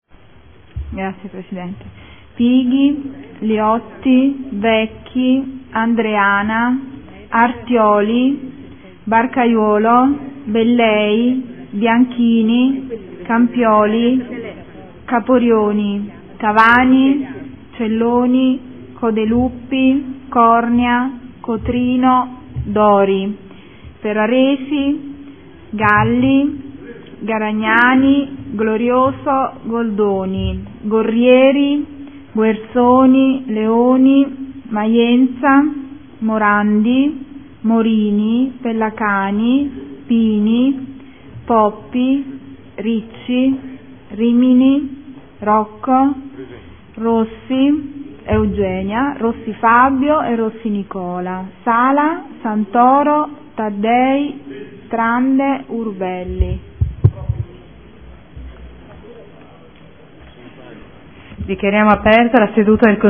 Seduta del 06/02/2014. Appello.